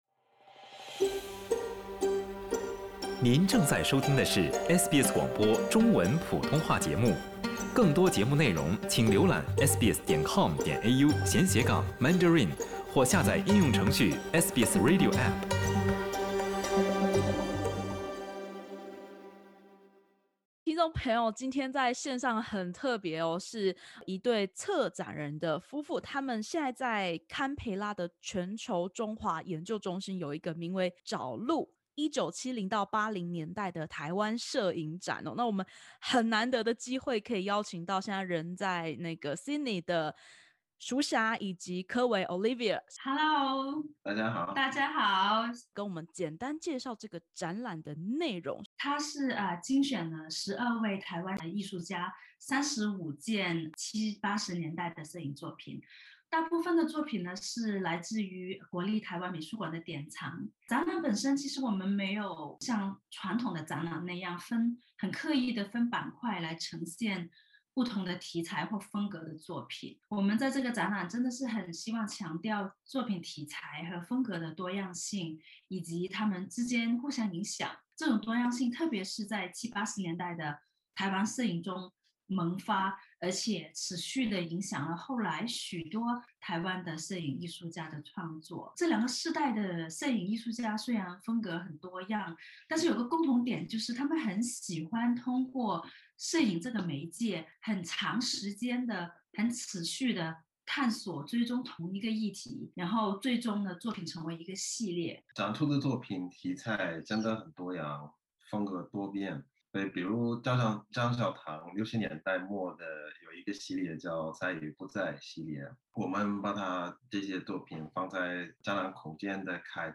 澳洲国立大学正在展示35幅从台湾运至澳洲的1970-80年代摄影作品，点击首图收听采访音频。